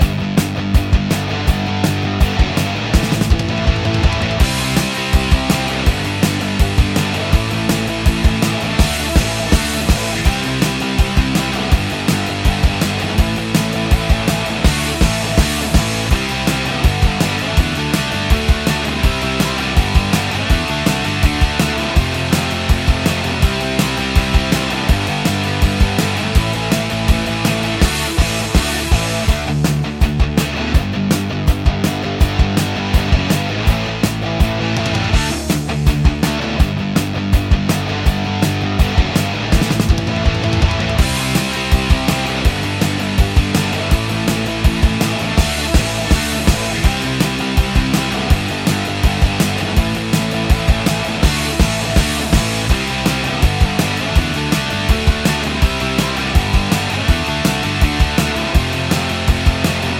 no Backing Vocals Punk 3:24 Buy £1.50